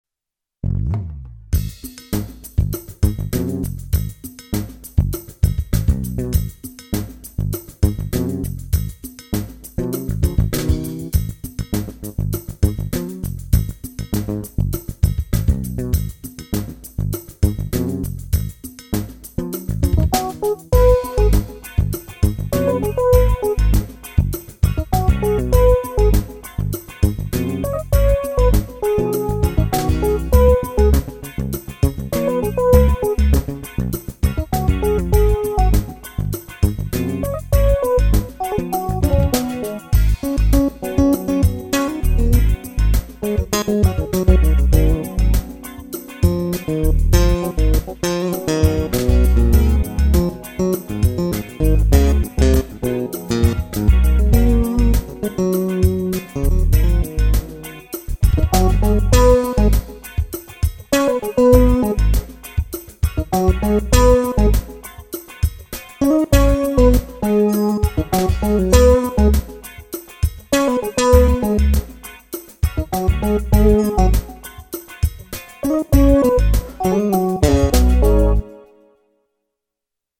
All of the samples were DI'd (direct injected) for sampling.